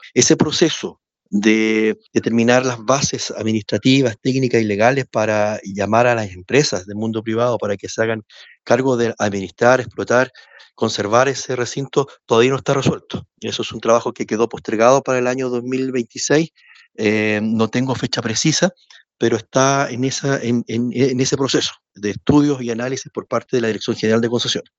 Ahora, el proyecto fue incorporado al sistema de concesiones, junto a otros cuatro aeródromos del sur del país, lo que mantiene suspendidas las mejoras al terminal aéreo de Osorno. Actualmente, todo se encuentra en estudio, confirmó el seremi de Obras Públicas en Los Lagos, Juan Fernando Alvarado: